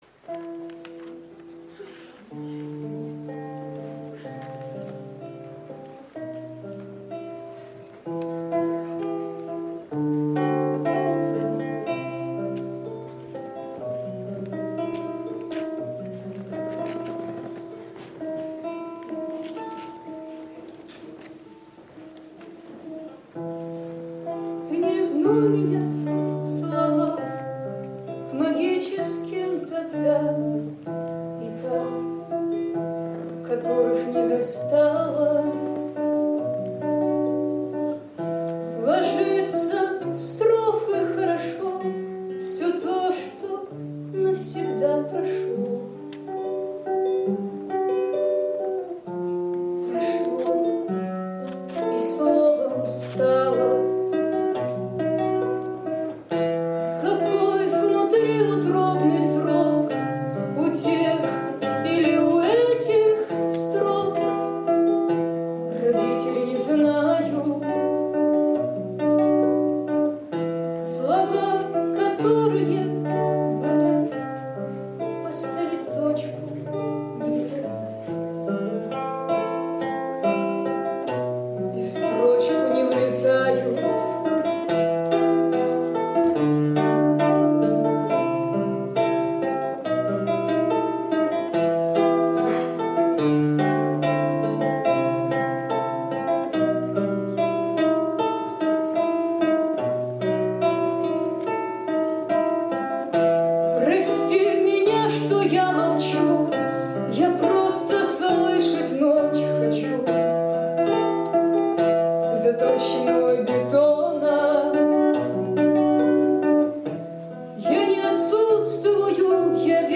концерт в Камерном зале СГУ 28.